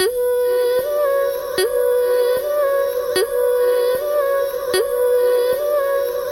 Tag: 152 bpm Electronic Loops Vocal Loops 1.06 MB wav Key : Unknown